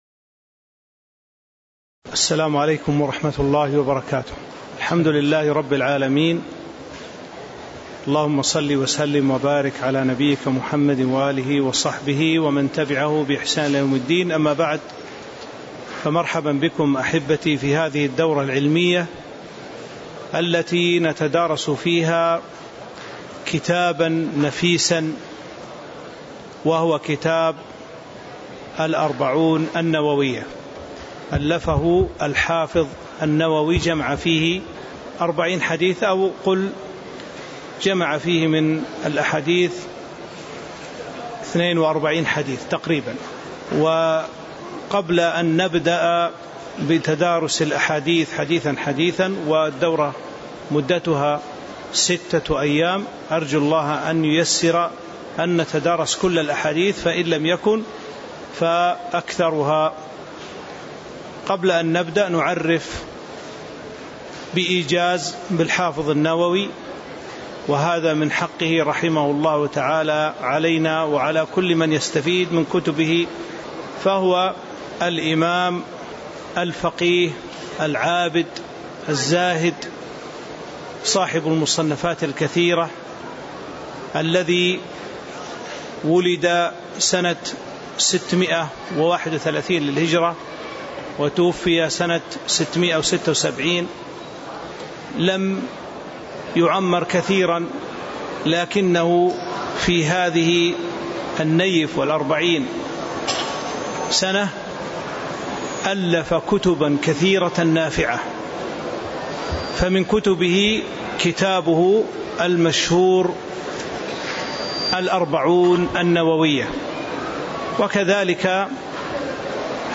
تاريخ النشر ١١ محرم ١٤٤٥ هـ المكان: المسجد النبوي الشيخ